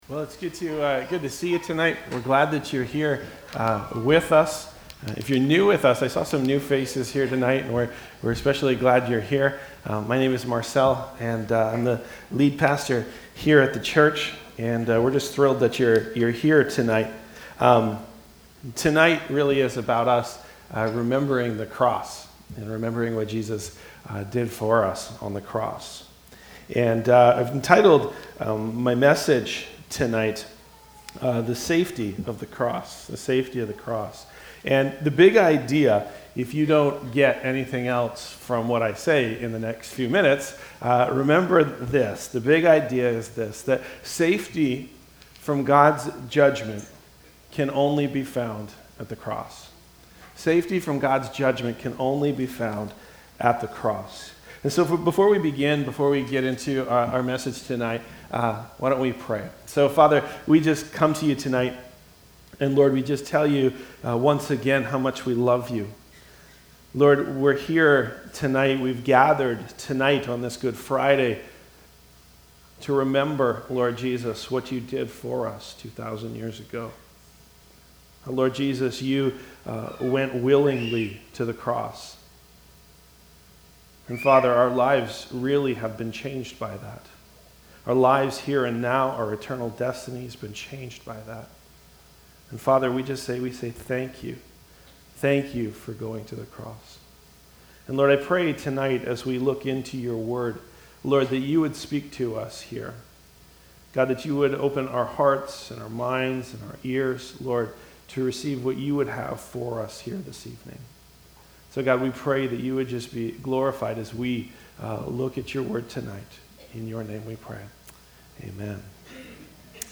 Valley Church - Lynn Valley - North Vancouver - Sermons